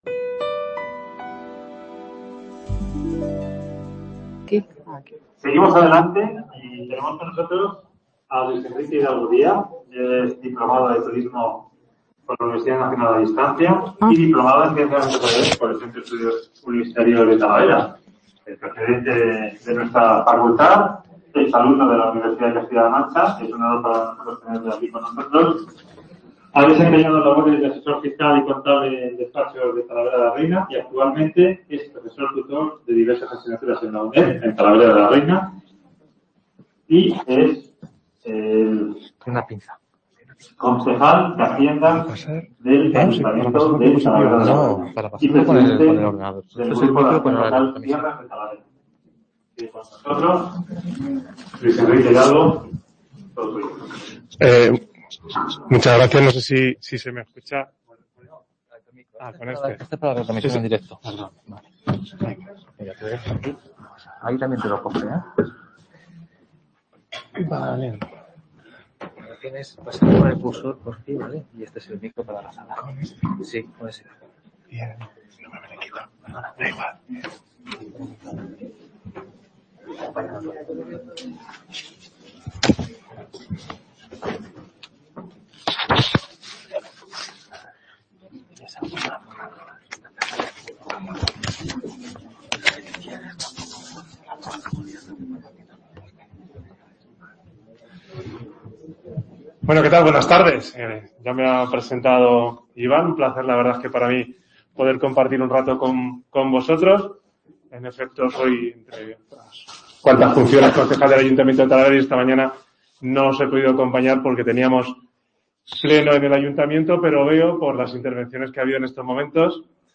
Jornadas dedicadas a la Economía Circular en el Mundo Rural, organizadas por la UCLM en colaboración con la UNED de Talavera de la Reina. Un espacio abierto de diálogo y propuestas para el desarrollo sostenible de las zonas rurales.